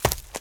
STEPS Leaves, Run 18.wav